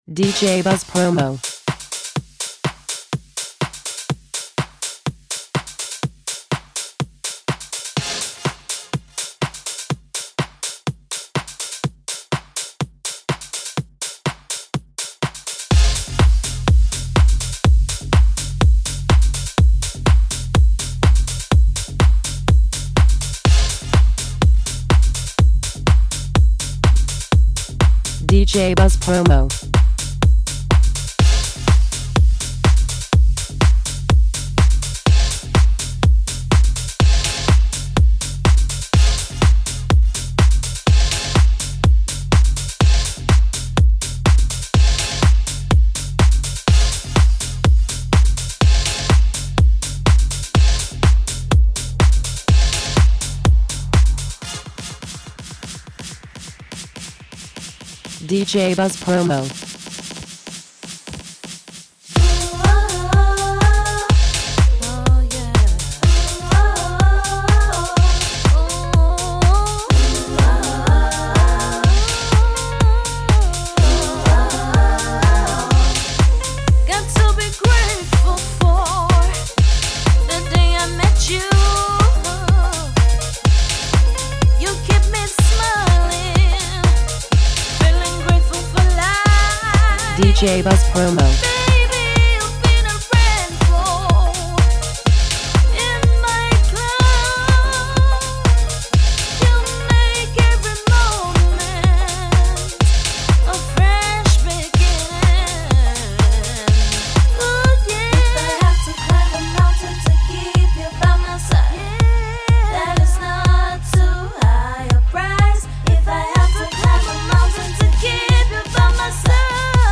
A French Touch to House!
The perfect soundtrack for your summer season!